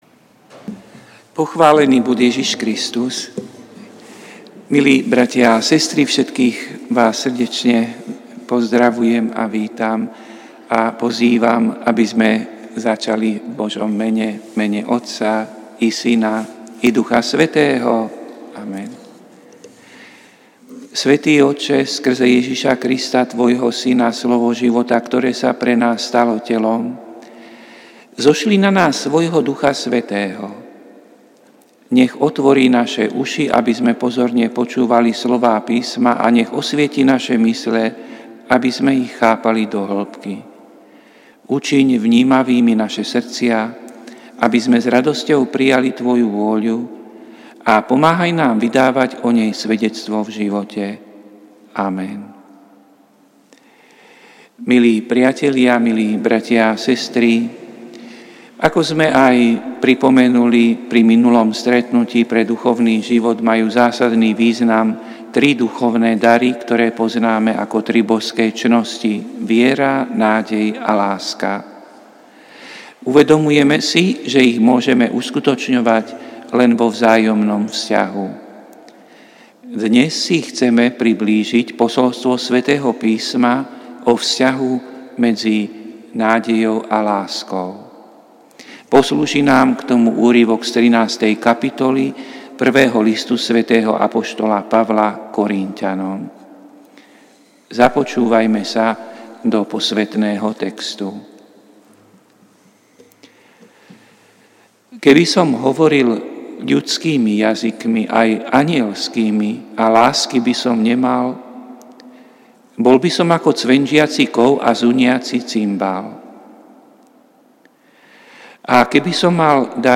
Prinášame plný text a audio záznam z Lectio divina, ktoré odznelo v Katedrále sv. Martina 4. decembra 2024.